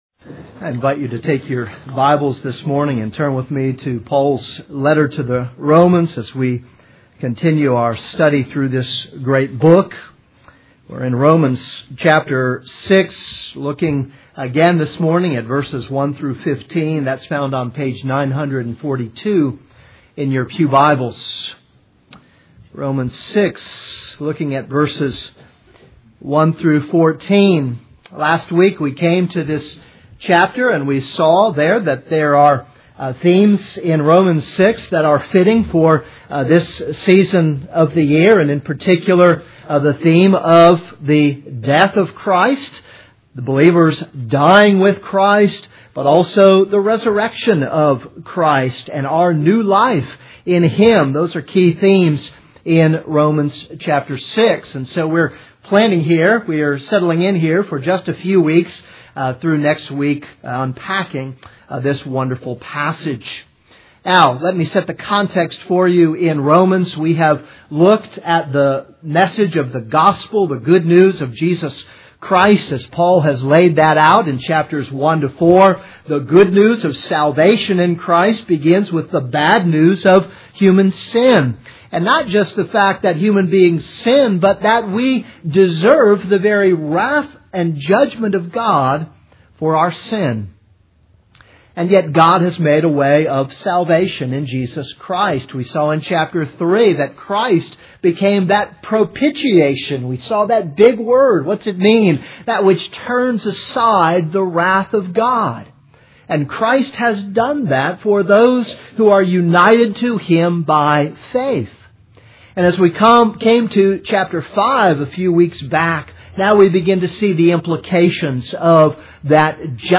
This is a sermon on Romans 6:1-14.